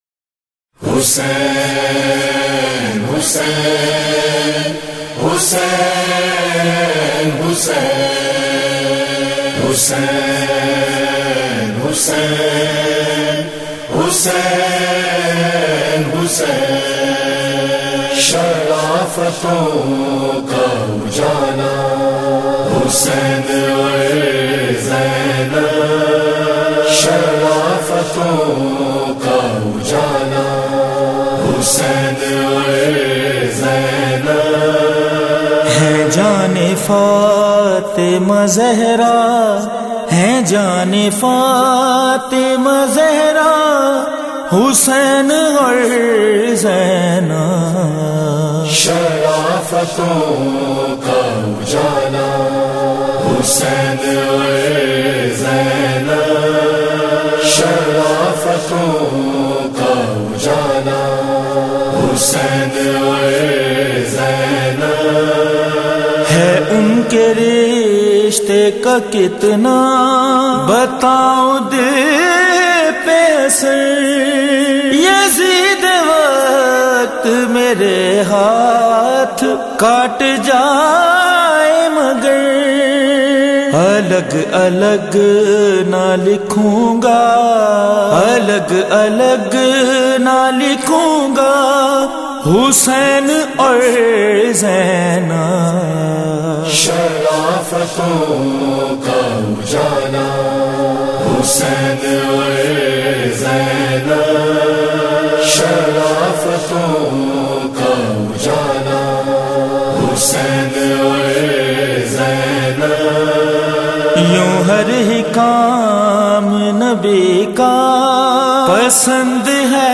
منقبتیں اور قصیدے